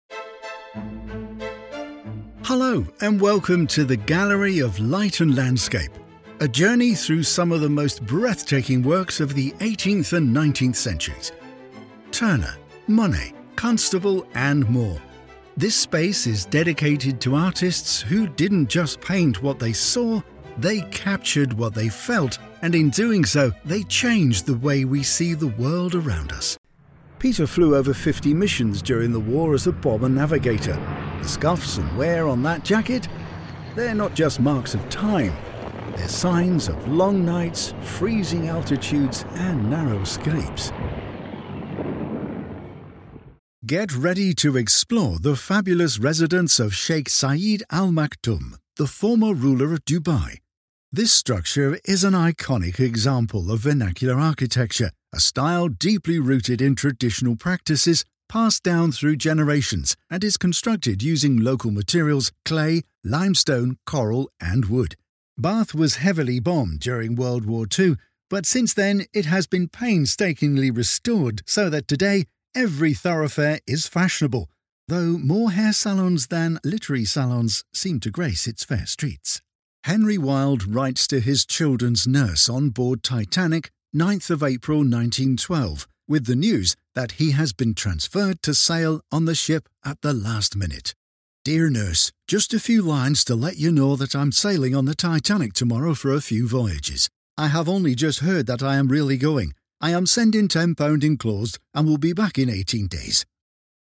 Male
Older Sound (50+)
With over a decade of experience, I have a warm, trustworthy, and versatile British male voice with a natural RP accent.
Broadcast-quality audio from a professional home studio with fast turnaround and seamless delivery.
Audio Tour Guide Mix